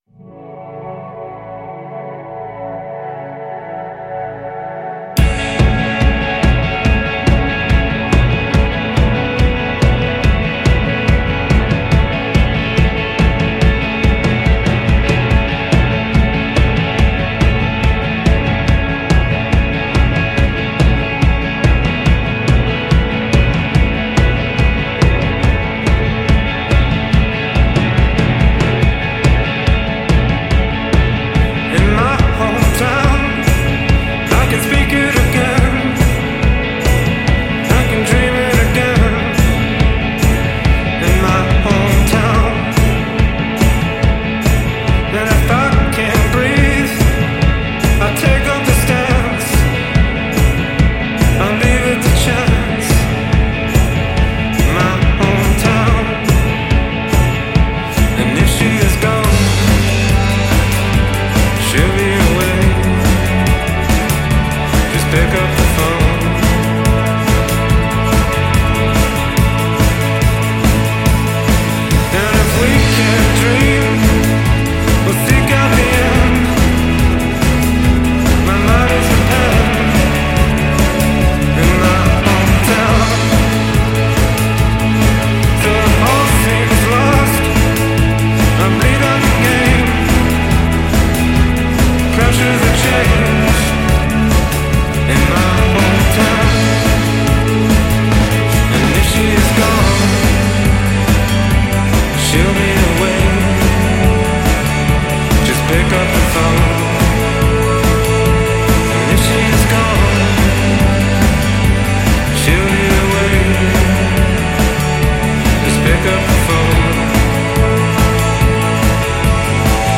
арт рок, поп панк, пост панк